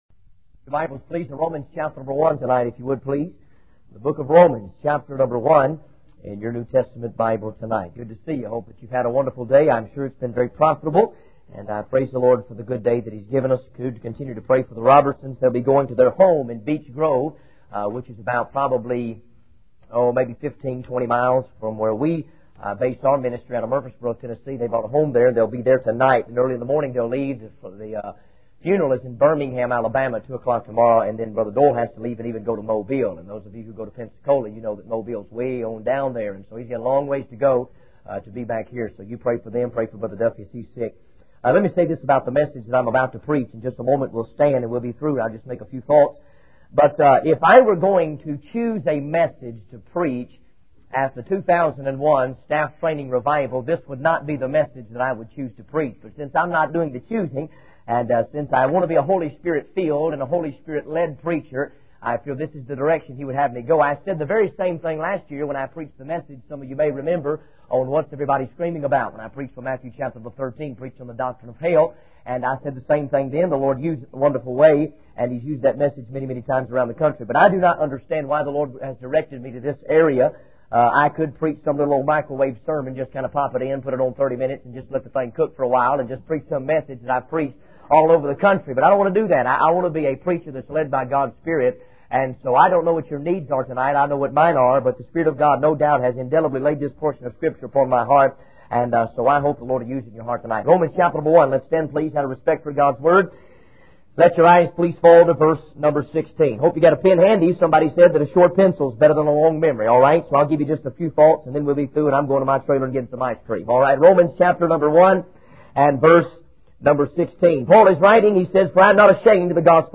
In this sermon, the preacher starts by quoting Romans 1:16, emphasizing that he is not ashamed of the gospel. He explains that the gospel is the power of God for salvation to everyone who believes.